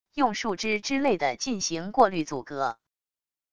用树枝之类的进行过滤阻隔wav音频